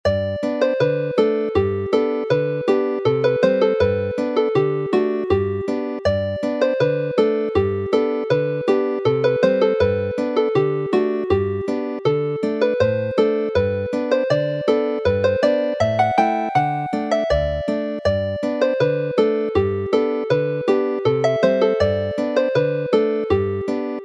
Chwarae'r alaw yn G
Play the melody in G